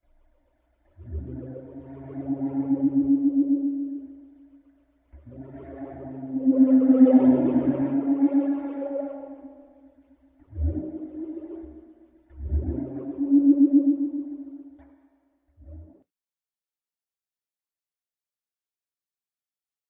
Electronic whale
Electronic underwater whale sounds.
32kbps-Triond-whale.mp3